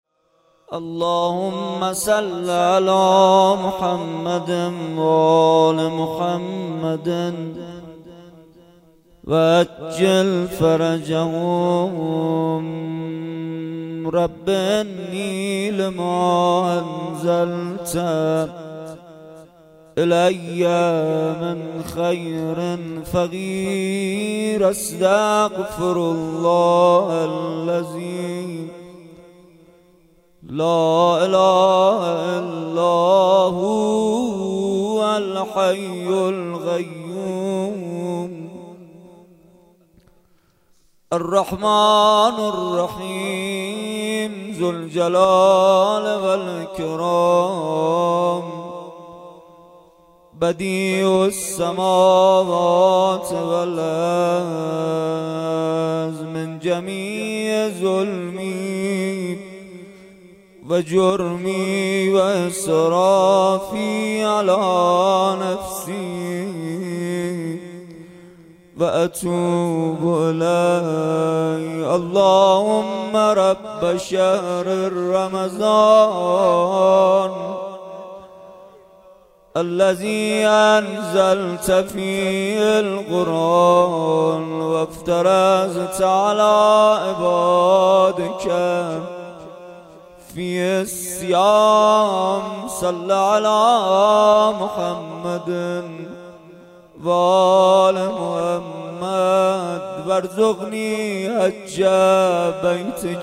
بخش اول-مناجات و روضه